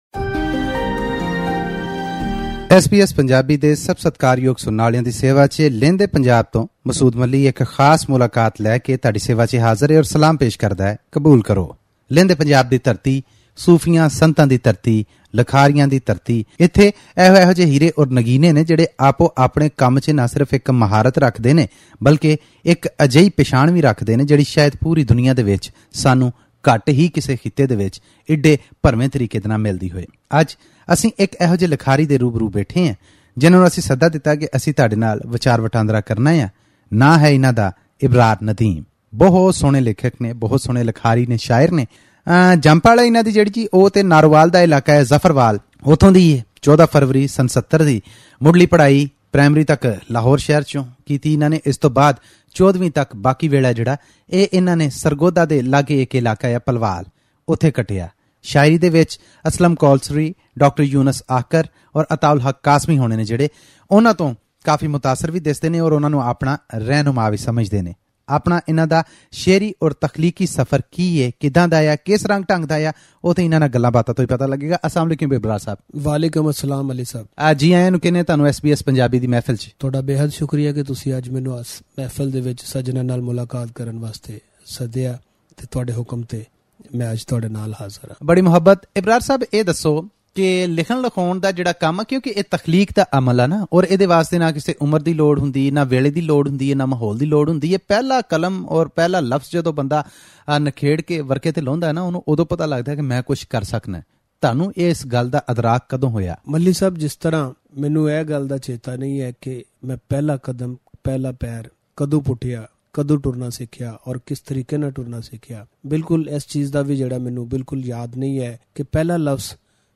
Listen to this interview in Punjabi by clicking on the player at the top of the page.